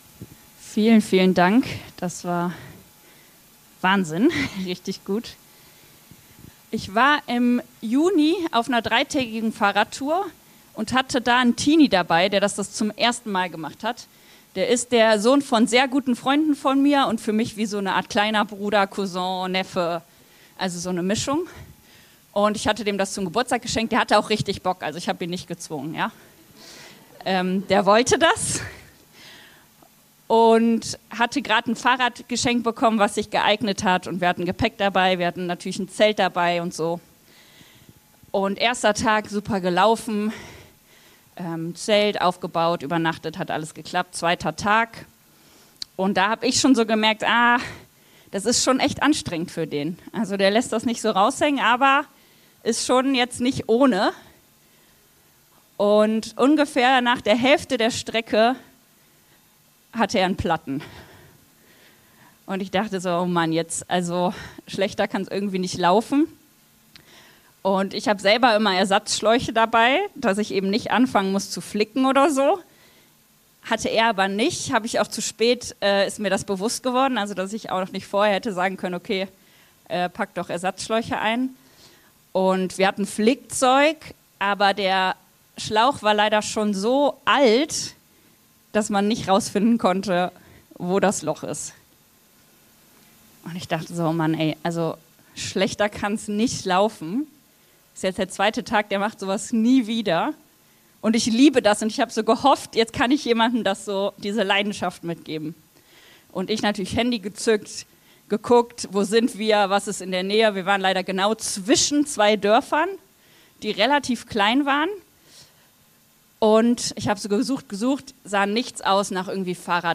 Liebe deinen Nächsten und du wirst leben ~ Predigt-Podcast von unterwegs FeG Mönchengladbach Podcast